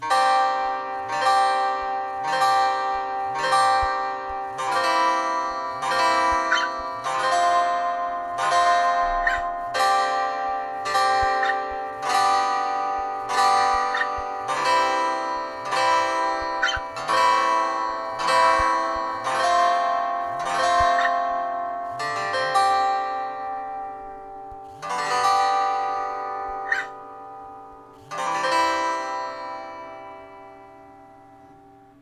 Choisissez les accords dont vous aurez besoin et pincez les cordes, soit rapidement, soit plus lentement pour faire des arpèges. Voici un aperçu audio, enregistré par mes soins en externe, avec un micro (on entend un peu le doigt qui passe sur l'écran) :
- Choix des sons de guitare, deux seulement, actuellement, deux guitares folks.
les-meilleurs-applications-musique-2014-guitare.mp3